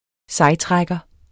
Udtale [ ˈsɑjˌtʁagʌ ]